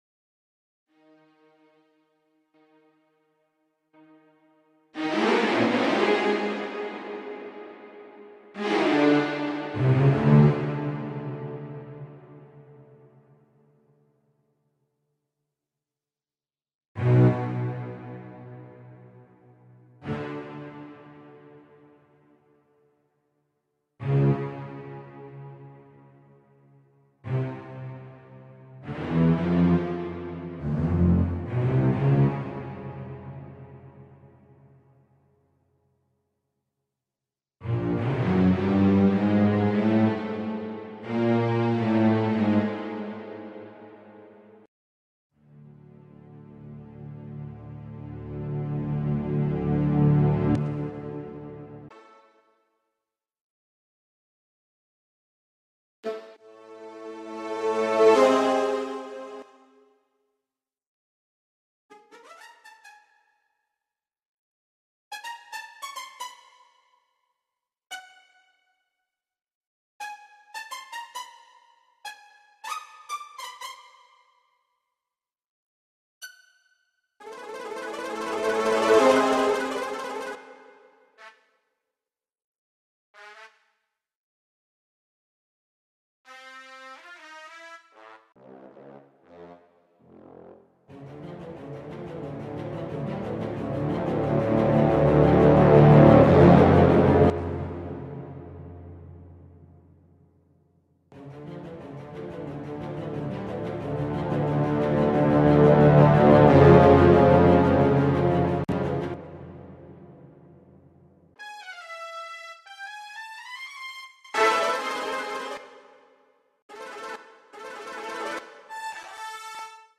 Orchestral Music